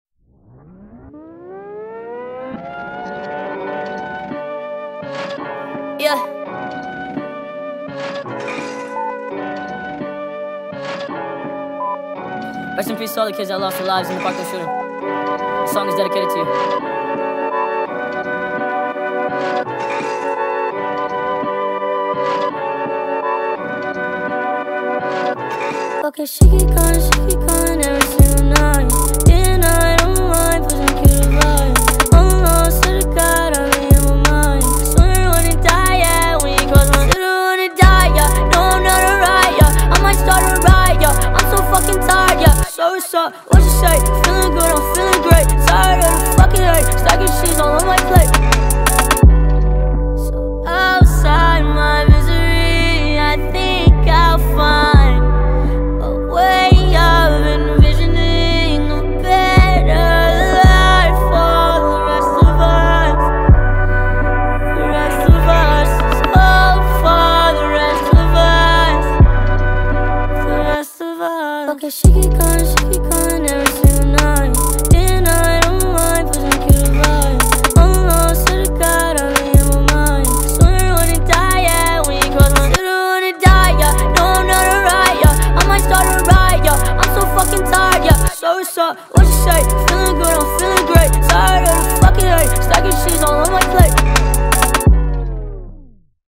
فانک
غمگین